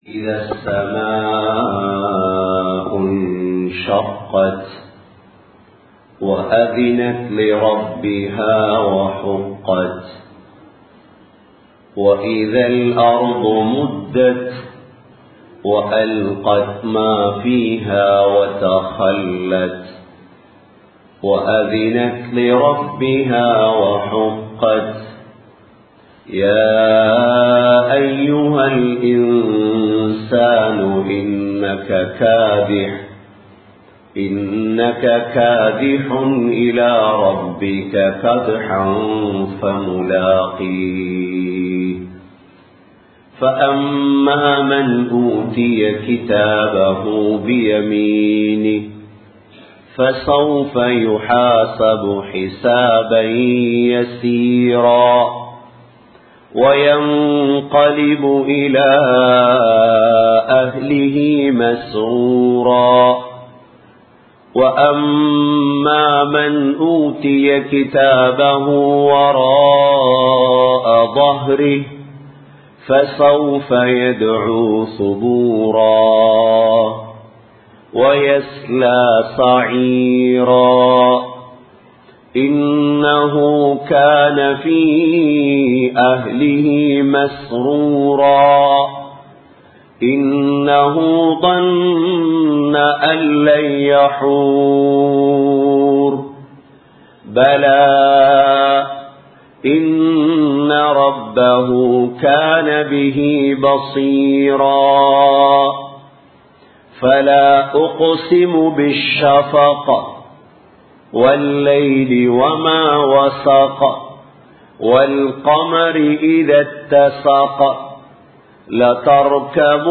Allahvai Pattriya Nambikkai (அல்லாஹ்வைப் பற்றிய நம்பிக்கை) | Audio Bayans | All Ceylon Muslim Youth Community | Addalaichenai
Samman Kottu Jumua Masjith (Red Masjith)